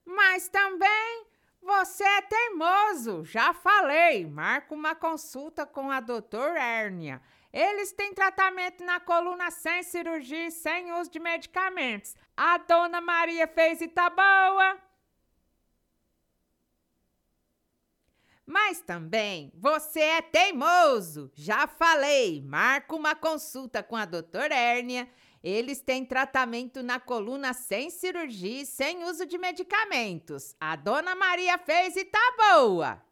velha :